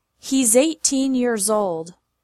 Here’s another example, this time with ‘s being pronounced as Z:
• He’zzzeighteen yearzold.